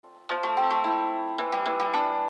• Качество: 320, Stereo
спокойные
без слов
Мелодия струнного инструмента на смс-ку...